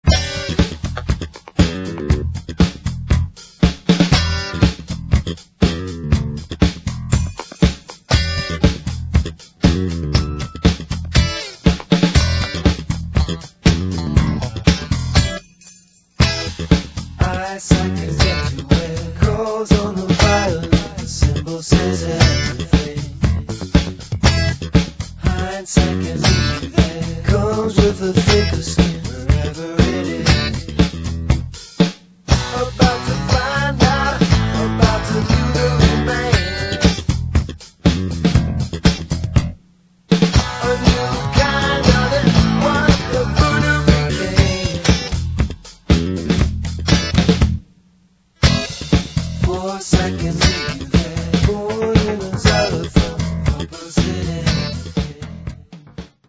abstract art-funk